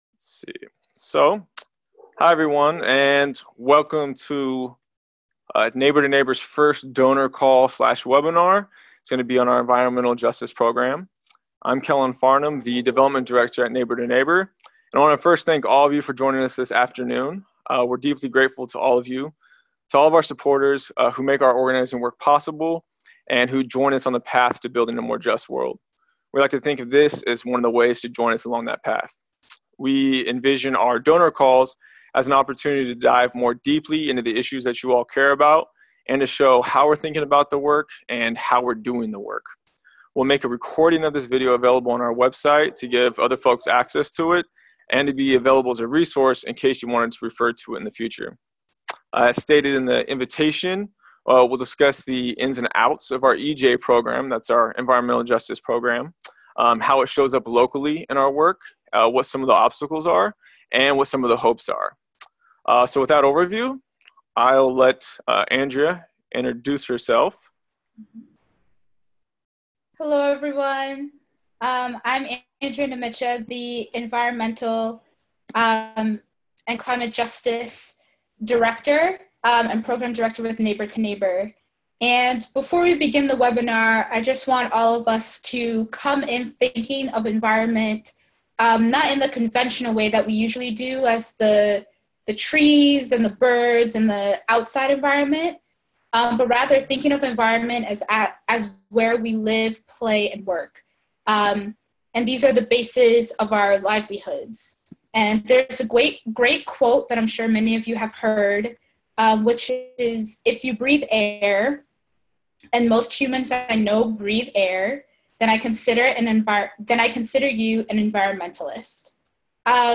In this webinar, our speakers offered an overview of N2N’s burgeoning environmental justice program, and shared insights from one of our local environmental justice campaigns. Our speakers also answered some great questions from those who were able to join the call.